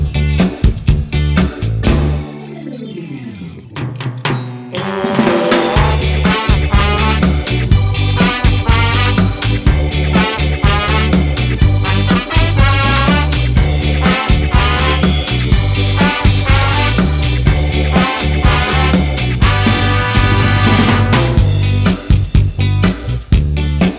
With Extra Snare And No Backing Vocals Ska 2:55 Buy £1.50